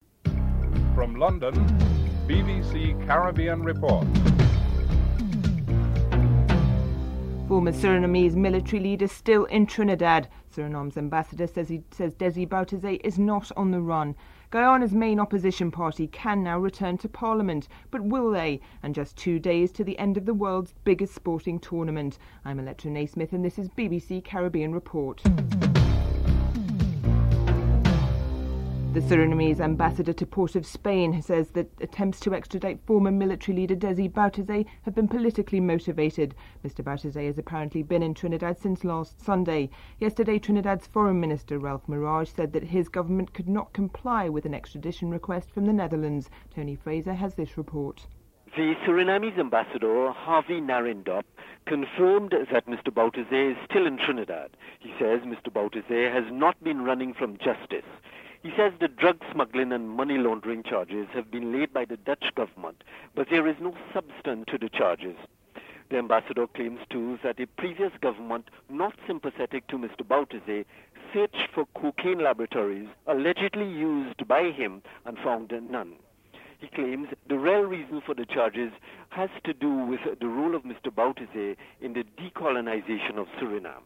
1. Headlines (00:00-00:28)
Prime Minister Owen Arthur is interviewed